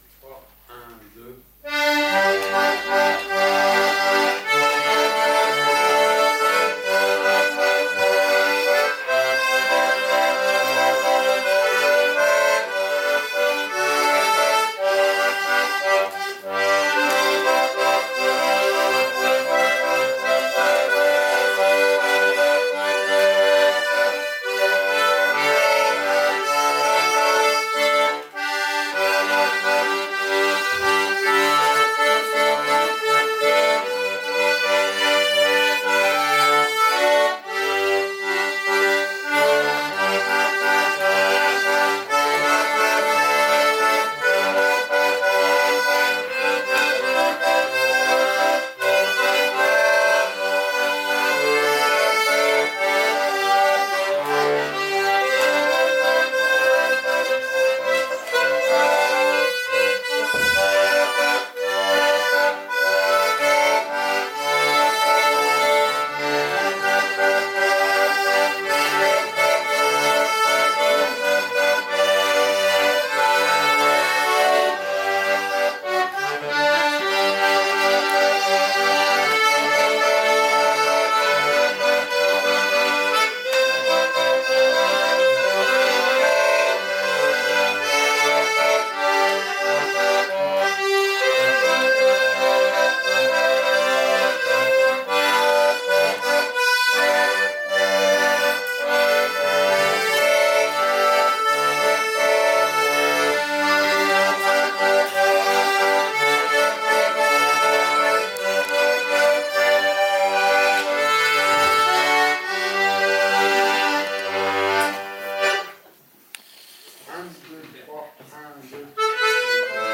Concert 25 février 2018
guitare
Extraits concert en mp3